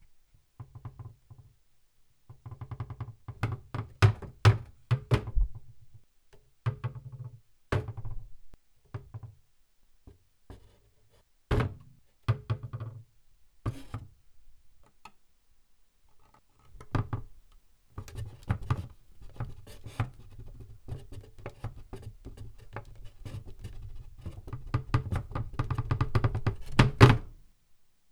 knocking_cupboard.wav